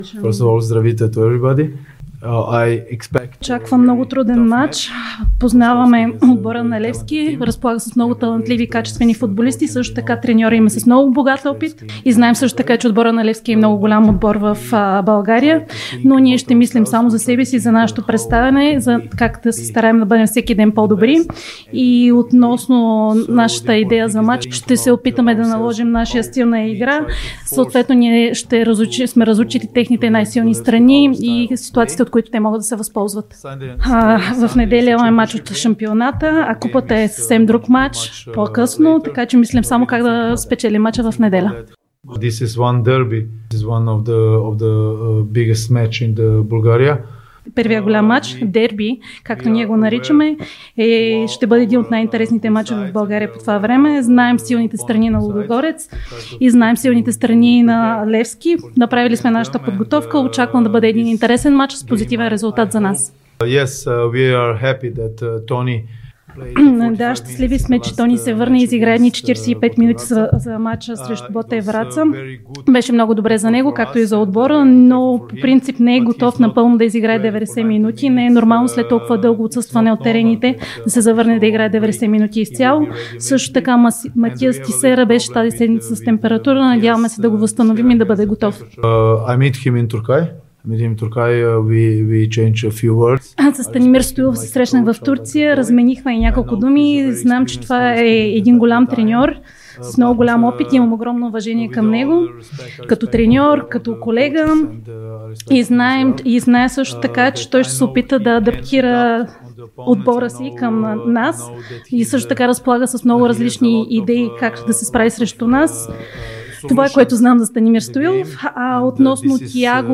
Старши треньорът на Лудогорец Анте Шимунджа даде пресконференция преди домакинството срещу Левски в неделя. Той говори и за срещите си със Станимир Стоилов.